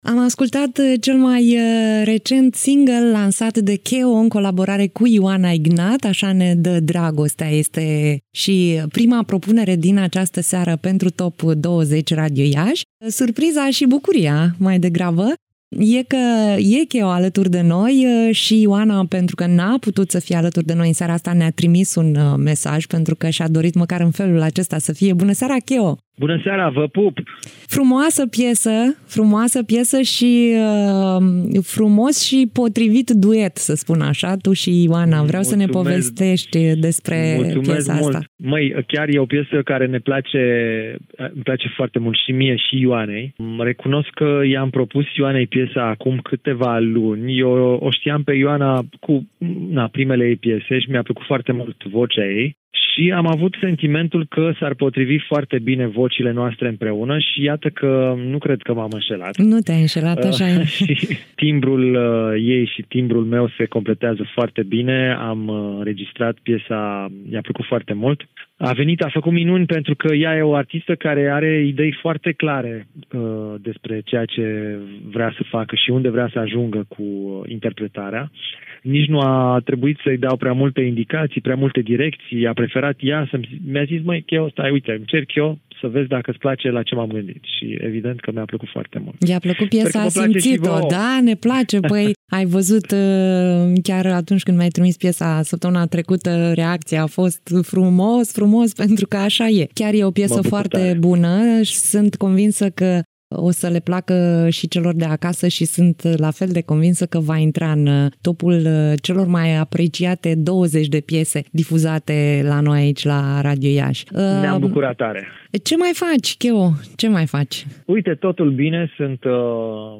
Ioana Ignat nu a putut intra în direct, dar a ținut să fie totuși alături de ascultătorii Radio Iași printr-un mesaj, pe care îl puteți asculta la finalul interviului cu Keo.